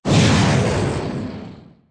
rocket.ogg